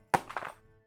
UseBook.ogg